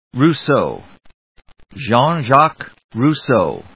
/ruːsóʊ(米国英語), Jeanóʊ(英国英語)/